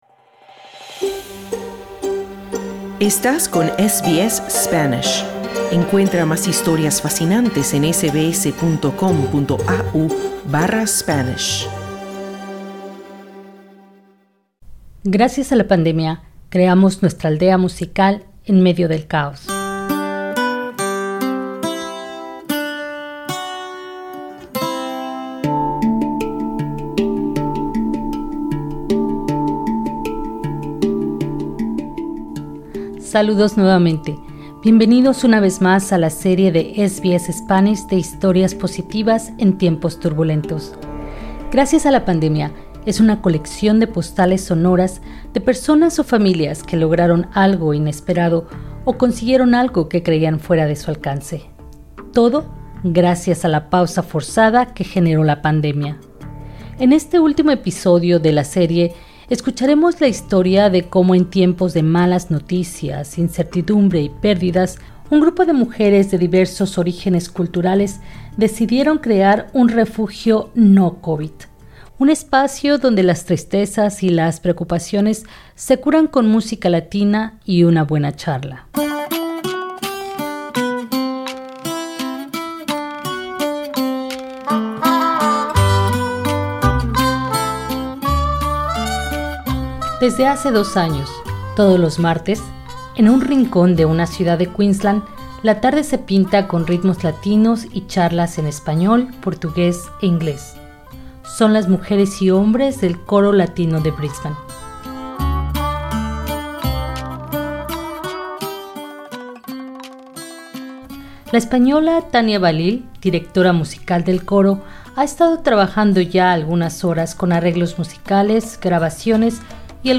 Presiona en el ícono que se encuentra sobre esta imagen para escuchar la historia en la voz de sus protagonistas.